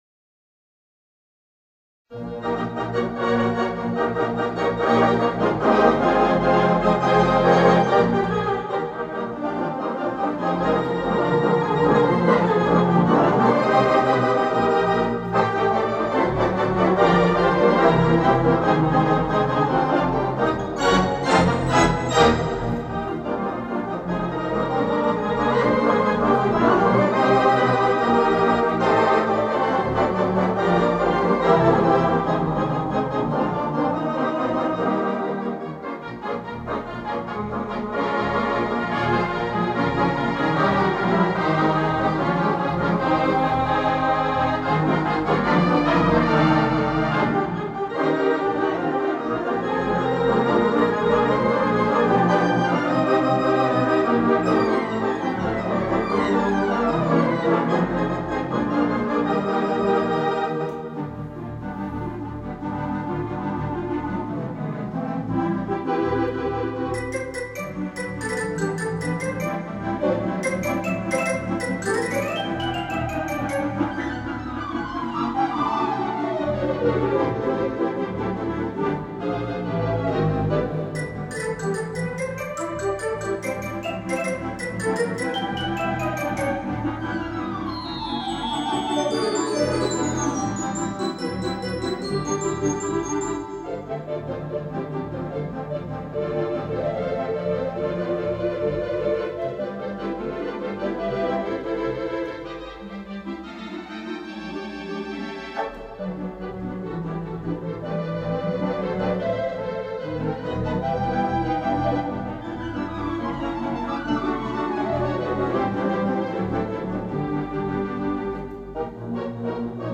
Digital Theatre Organ
The First Concert